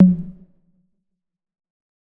Tom_E2.wav